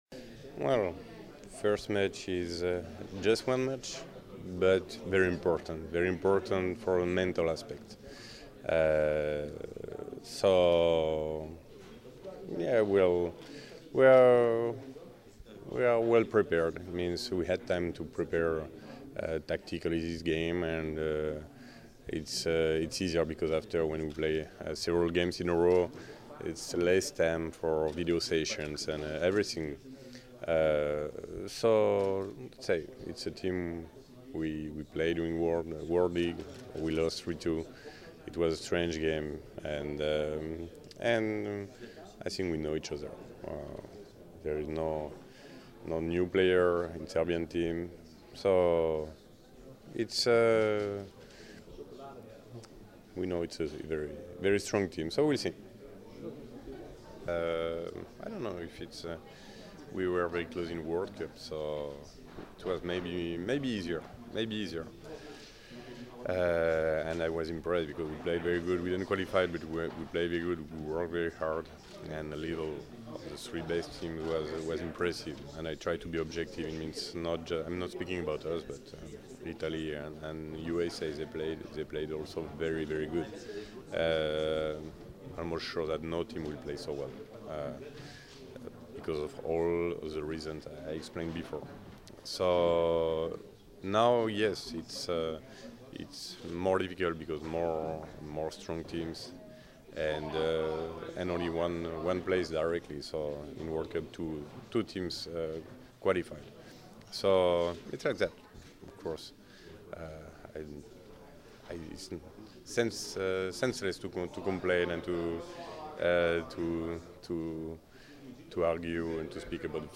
Muška seniorska reprezentacija Srbije trenirala je danas (ponedeljak, 4. januar) u dvorani “Maks Šmeling” od 12,00 – 14,00 časova, a pre toga, od 11,00 časova održana je konferencija za novinare, kojoj su prisustvovali treneri svih 8 reprezentacija učesnica turnira Evropskih kvalifikacija za OI 2016, koji će se odigrati od 5. – 10. januara.
IZJAVA STEFANA ANTIGE, SELEKTORA POLJSKE